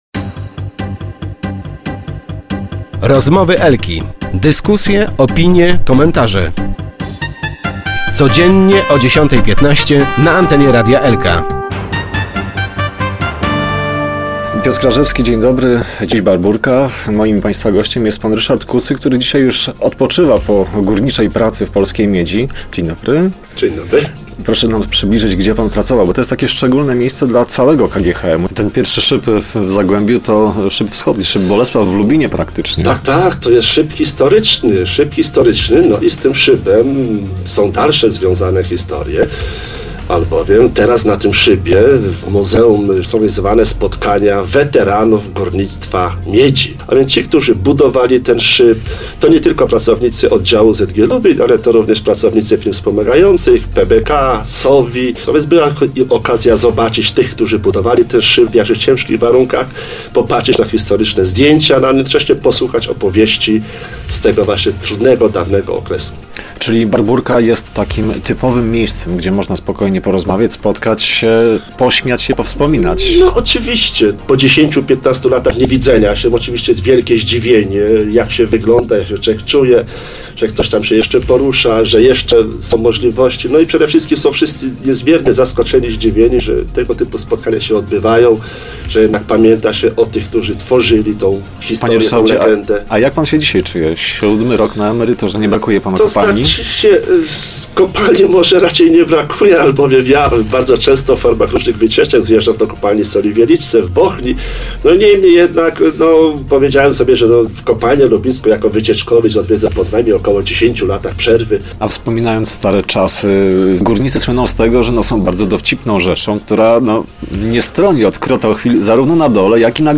Start arrow Rozmowy Elki arrow Górnicze tradycje